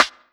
• Good Snare D Key 26.wav
Royality free steel snare drum sound tuned to the D note. Loudest frequency: 2142Hz
good-snare-d-key-26-dA9.wav